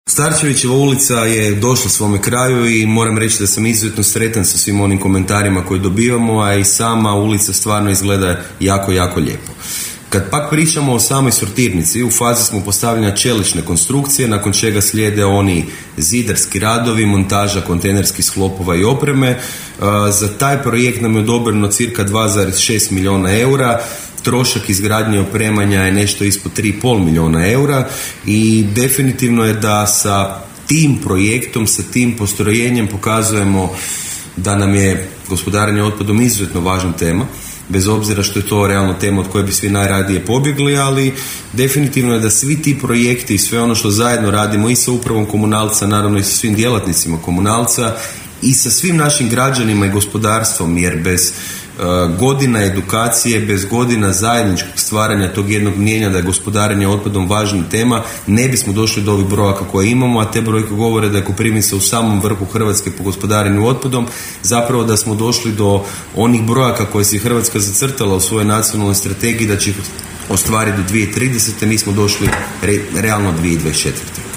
KOPRIVNICA Gradonačelnik Jakšić govorio o radovima na području grada
U emisiji Koprivničke teme, gost je bio gradonačelnik grada Koprivnice Mišel Jakšić, koji je govorio o velikim građevinskim radovima na području Koprivnice, puštanju u promet obnovljene Starčevićeve ulice, radovima na dogradnji OŠ „Braća Radić“, ali se osvrnuo i na radove na izgradnji sortirnice u Herešinu.